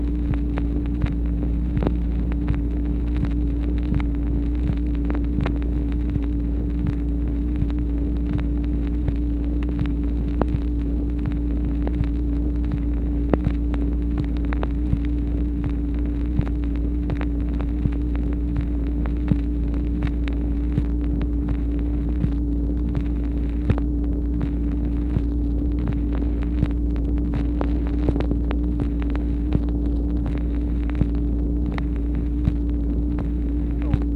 MACHINE NOISE, May 27, 1964
Secret White House Tapes | Lyndon B. Johnson Presidency